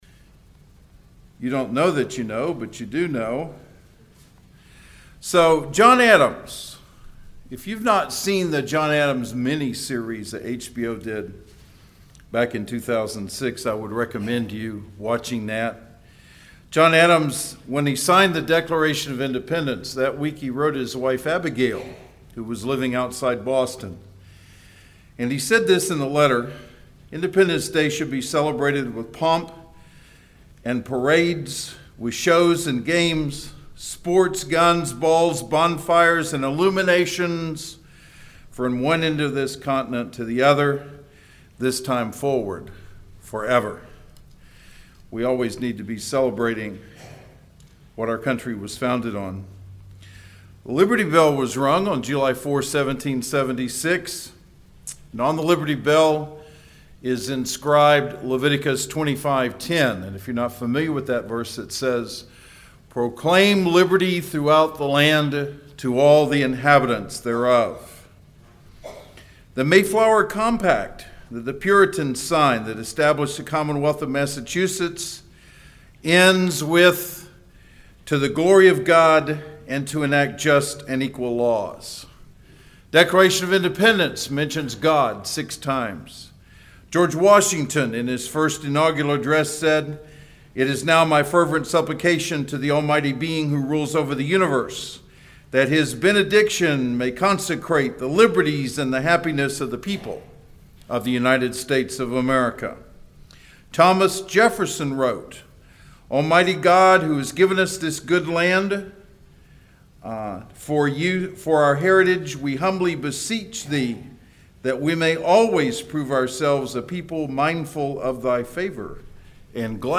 Inman Park Baptist Church SERMONS